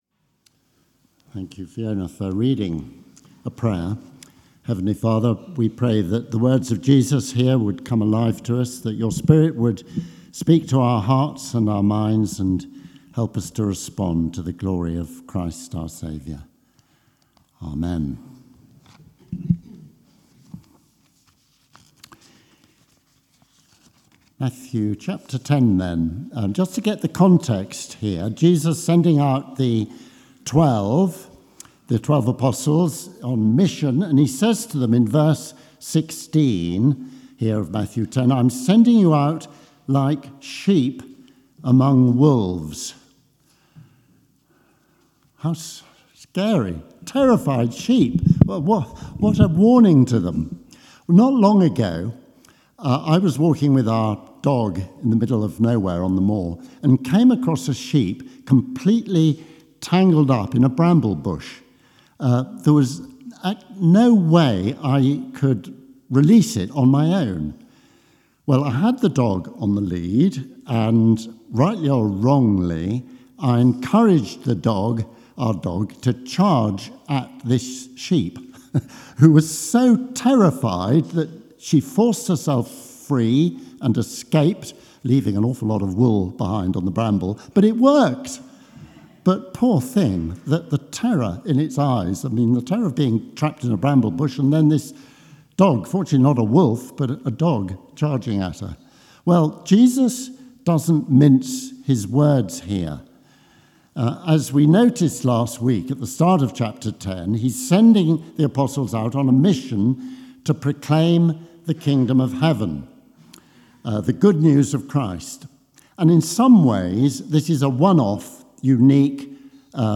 Matthew 10:17-31 Service Type: Sunday Service « Matthew 9v35-10v15 Matthew 10v32-11v1